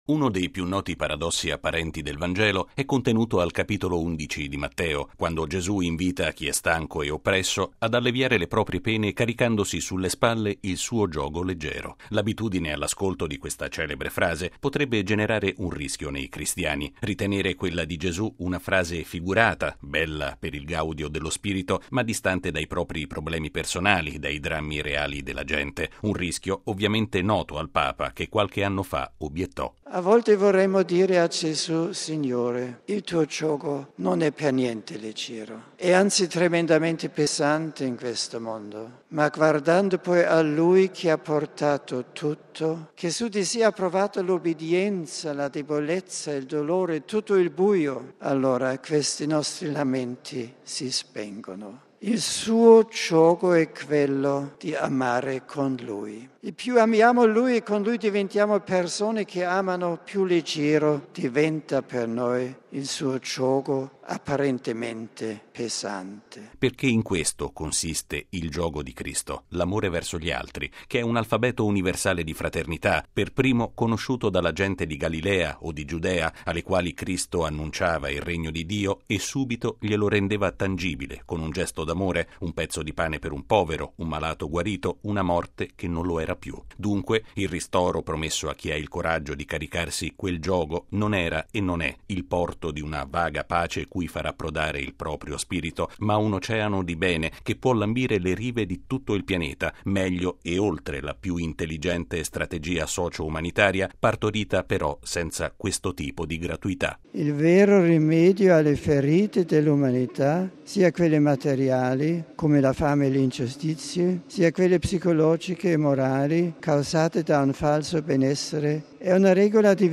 C’è anche l’anima da riposare e il senso cristiano del ristoro spirituale è tutt’altro che un invito a dimenticarsi del mondo e delle sue vicende a volte drammatiche, ma porta invece a importanti ricadute sociali. Il Papa lo spiegò bene in un Angelus di un anno fa, soffermandosi su un passo della liturgia che la Chiesa ripropone per la giornata di oggi.